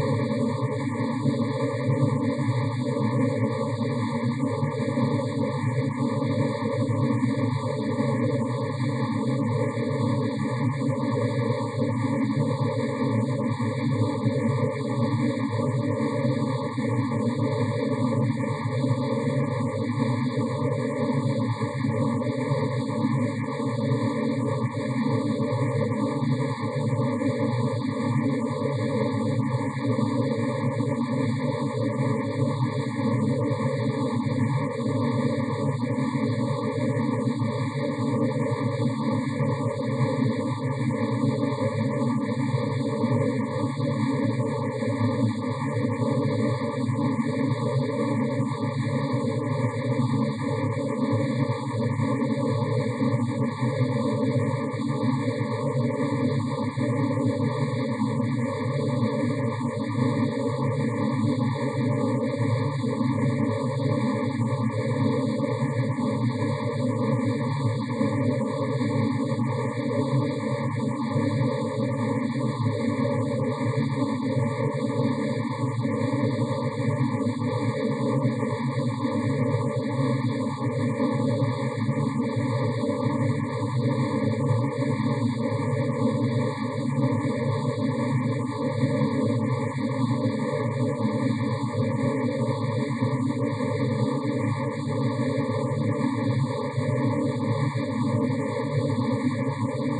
STIPA 测试信号
进行 STIPA 测量必须使用专用测试信号，它基于一个限宽男性语音频谱的随机噪声合成，测量时必须按规定的距离和大小进行播放。